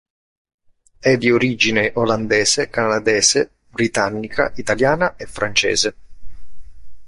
Pronunciado como (IPA) /ka.naˈde.ze/